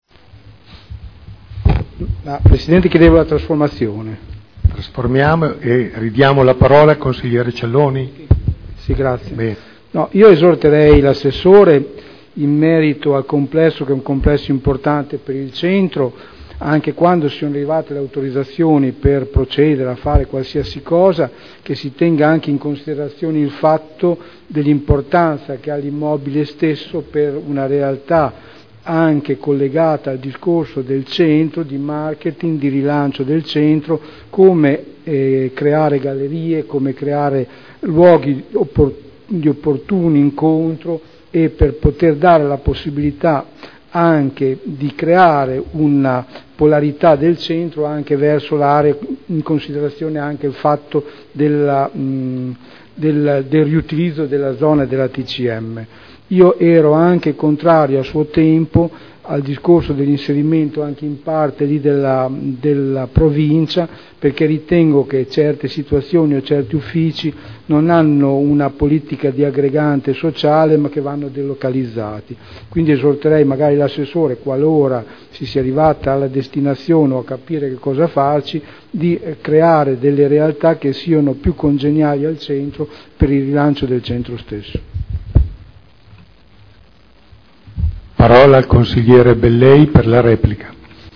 Sergio Celloni — Sito Audio Consiglio Comunale
Seduta del 28/03/2011.